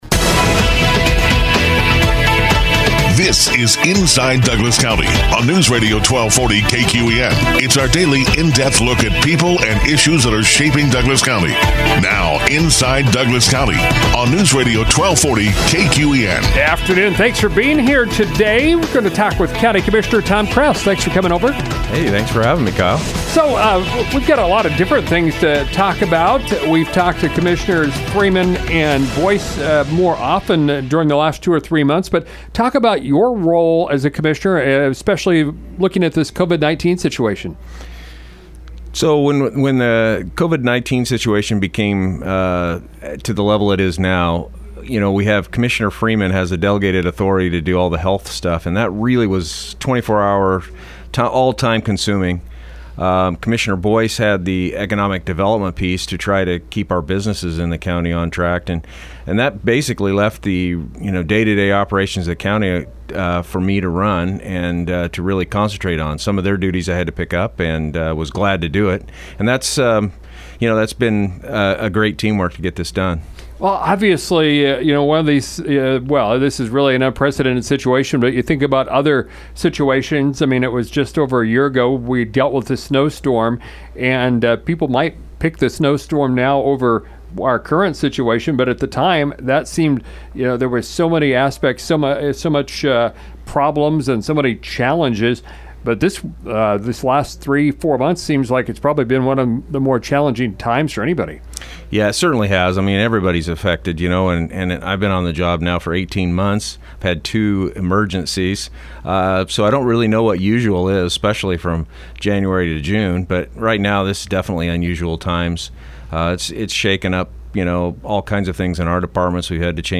Douglas County Commissioner Tom Kress with an update on the work he has been involved in for the past few months.